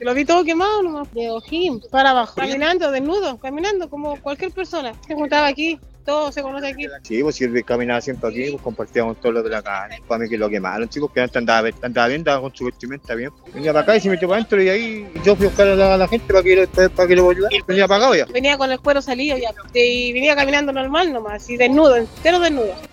testigo-hombre-quemado.mp3